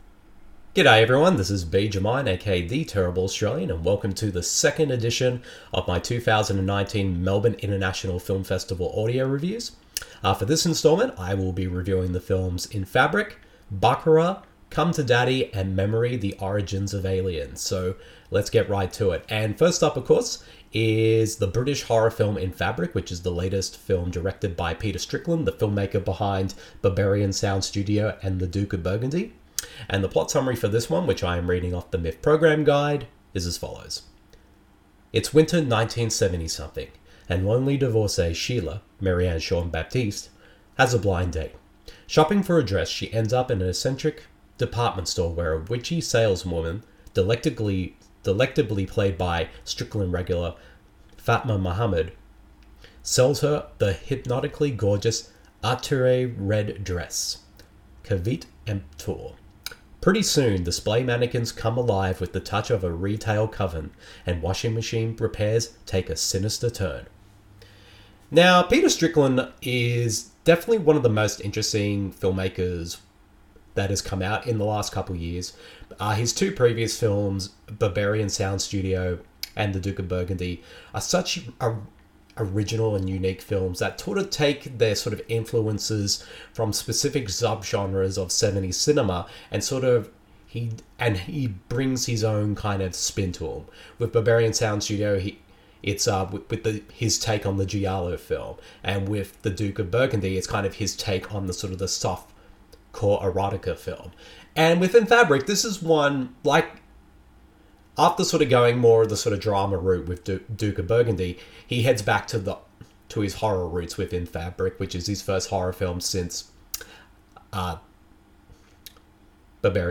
IN FABRIC, BACURAU, COME TO DADDY and MEMORY: THE ORIGINS OF ALIEN Welcome to the 2nd edition of my 2019 Melbourne International Film Festival (a.k.a. MIFF) audio reviews. In this one, I share my thoughts on the British horror film IN FABRIC, the unique Brazilian genre film BACURAU, the dark comedy COME TO DADDY and the ALIEN documentary MEMORY: THE ORIGINS OF ALIEN.